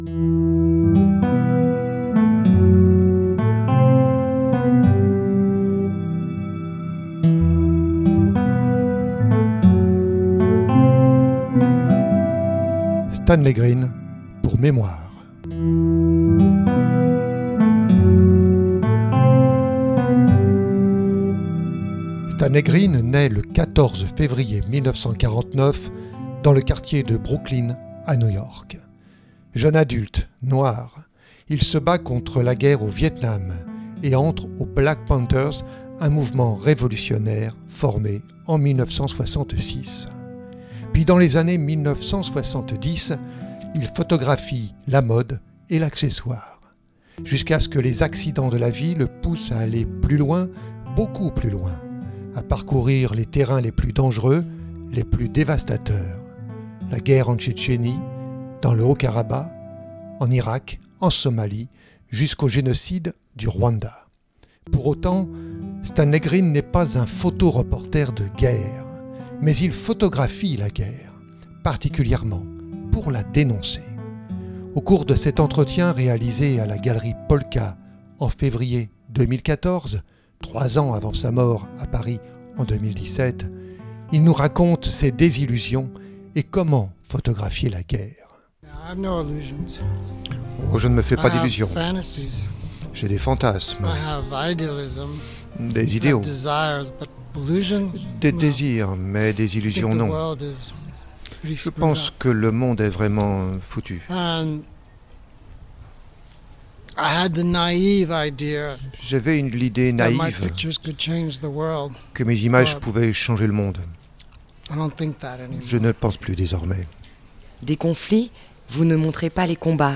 Entretien
Au cours de cet entretien réalisé à la galerie Polka en février 2014, 3 ans avant sa mort, à Paris, en 2017, il nous raconte ses désillusions et comment photographier la guerre.
Polka  galerie